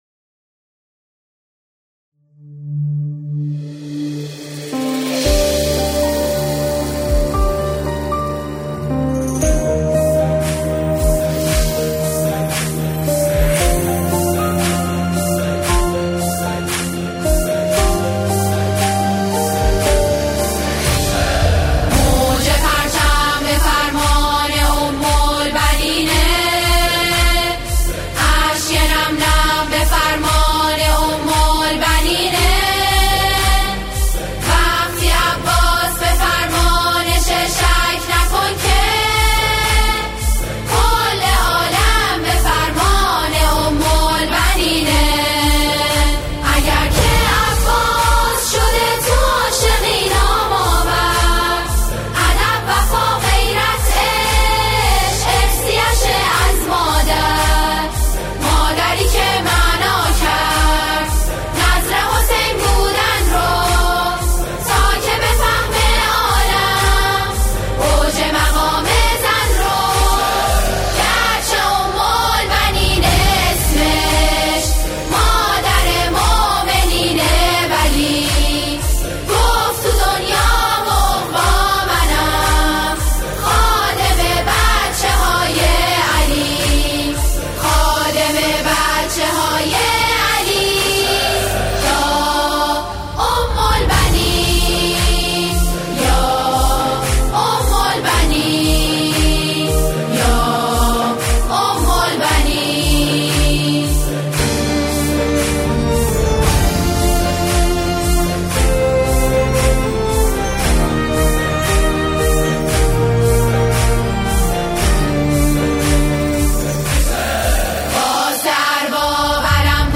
ژانر: سرود